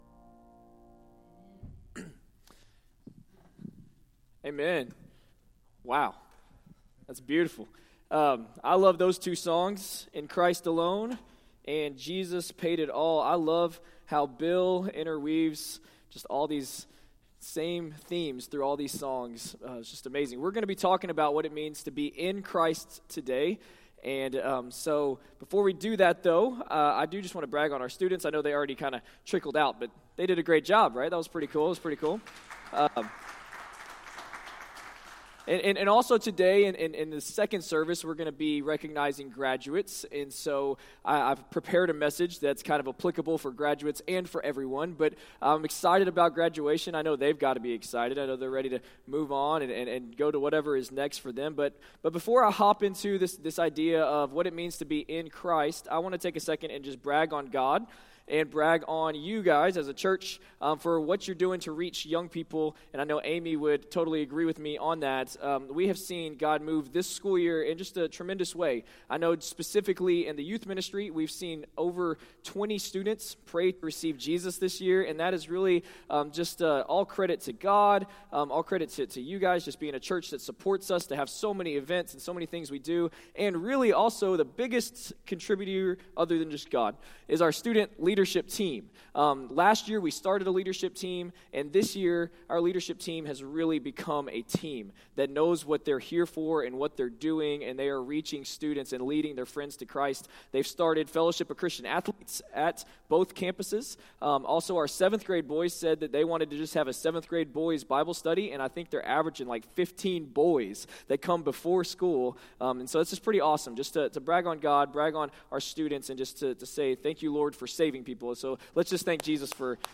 Sunday morning (5/21/17) sermon from FBC Grandview.